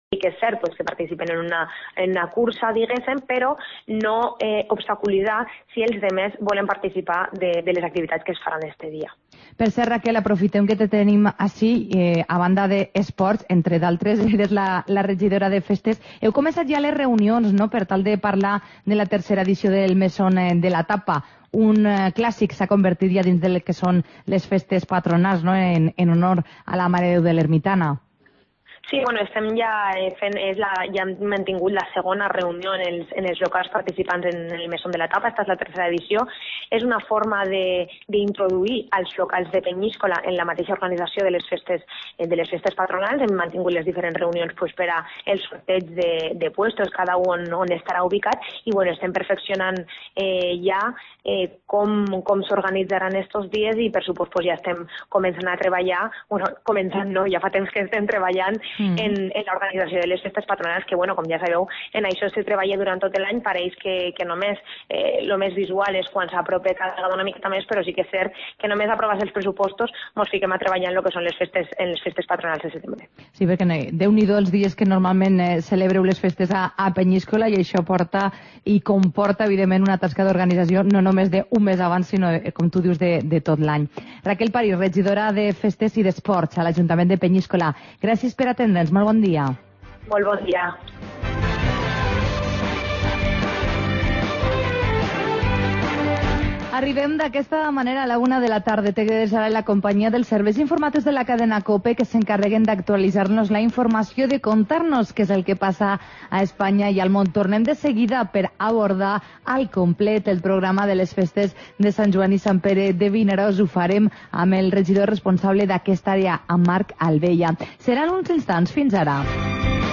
Magazine del Maestrat